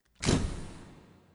Old Camera Sound 2.wav